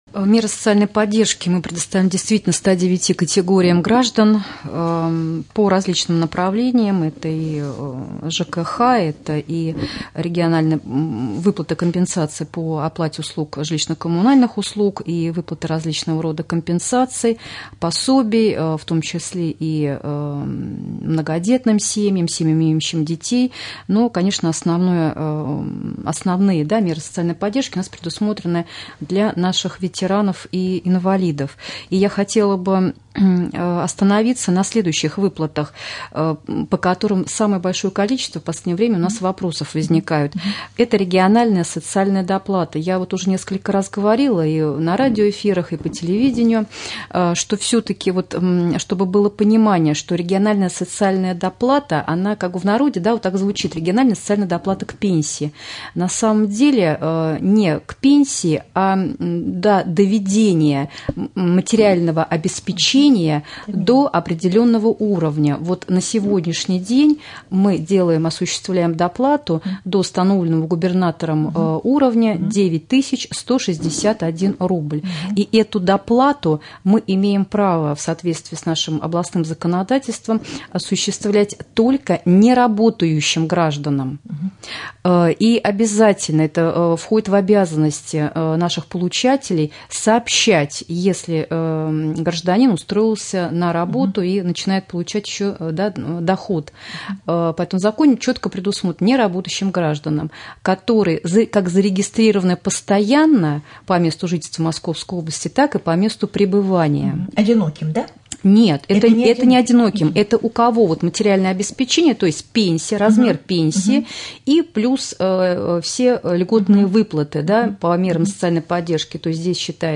О мерах социальной поддержки – региональная социальная доплата гражданам в прямом эфире Раменского радио рассказала начальник Раменского Управления социальной защиты населения Елена Костина. Она уточнила, что осуществляются доплаты в сфере оплаты жилищно-коммунальных услуг, выплаты в виде компенсаций, пособий, в том числе и многодетным семьям, семьям,имеющим детей. Но основные меры социальной поддержки предусмотрены для ветеранов и инвалидов.